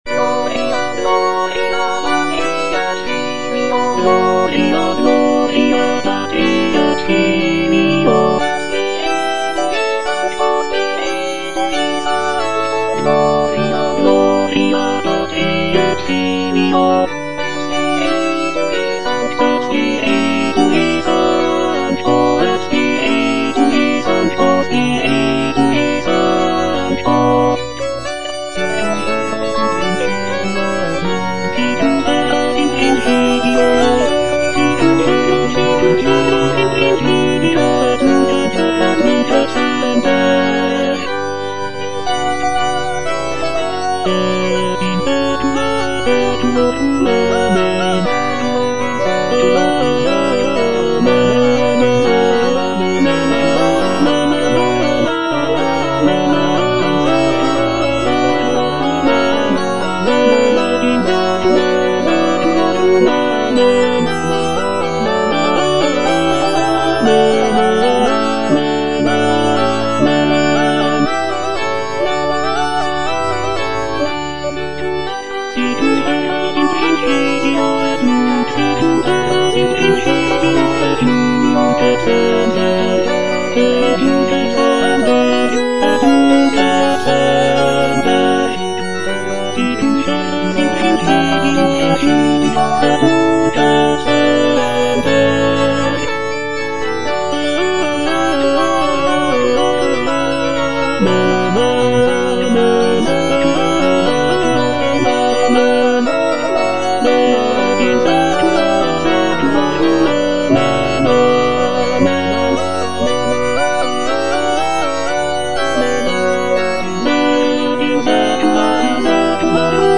M.R. DE LALANDE - CONFITEBOR TIBI DOMINE Gloria Patri - Tenor (Emphasised voice and other voices) Ads stop: auto-stop
"Confitebor tibi Domine" is a sacred choral work composed by Michel-Richard de Lalande in the late 17th century. It is a setting of the Latin text from Psalm 111, expressing gratitude and praise to the Lord. Lalande's composition features intricate polyphony, lush harmonies, and expressive melodies, reflecting the Baroque style of the period.